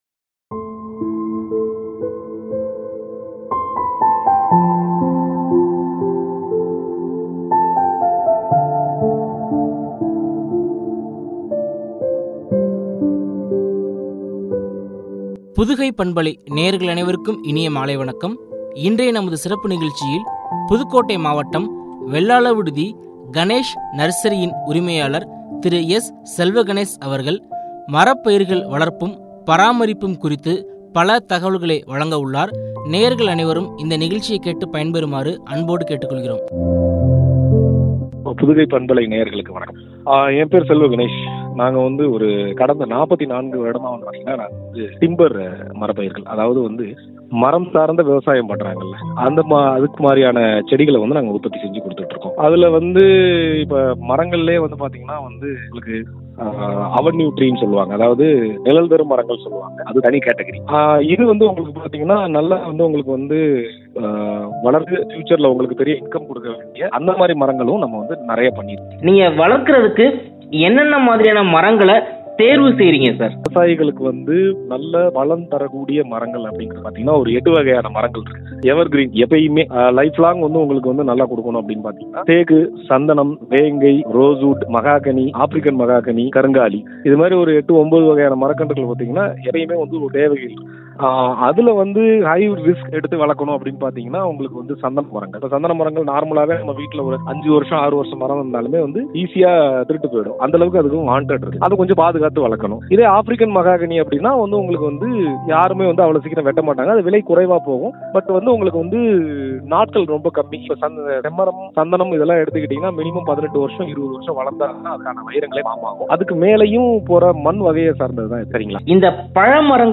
பராமரிப்பும்” குறித்து வழங்கிய உரையாடல்.